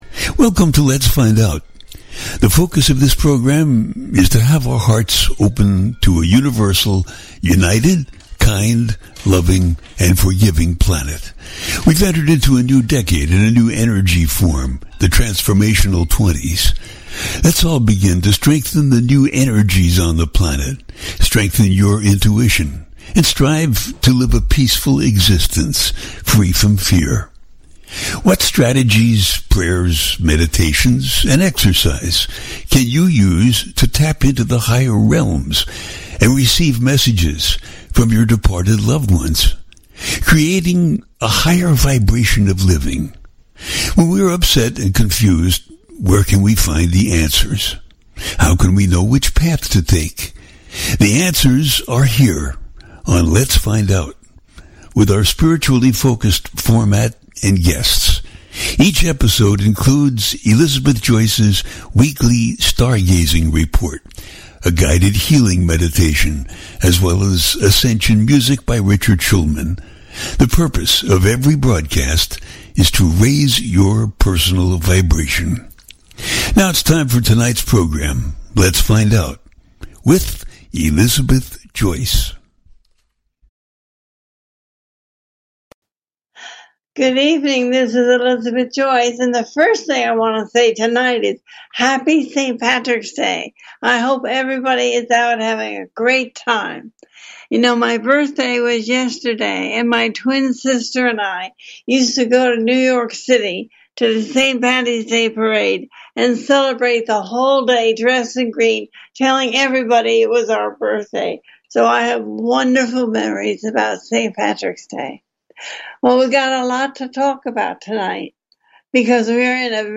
Talk Show Episode
The listener can call in to ask a question on the air.
Each show ends with a guided meditation.